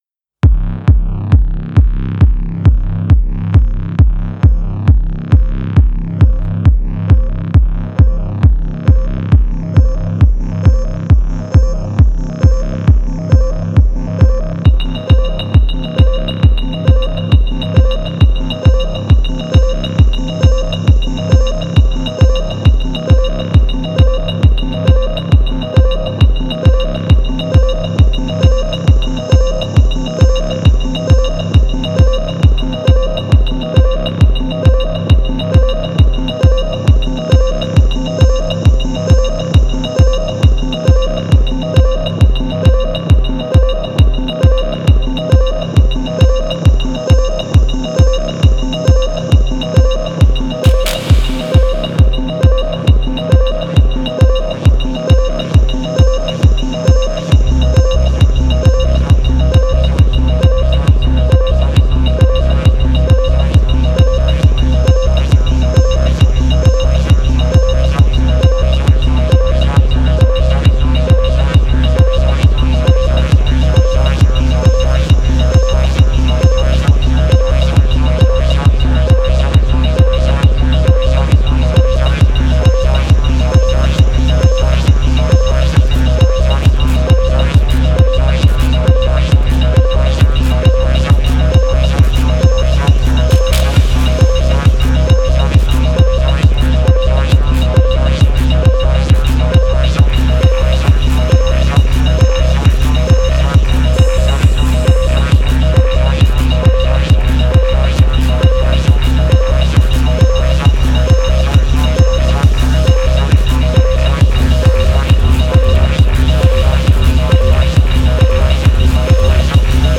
Genre: Tech House, Techno, Minimal.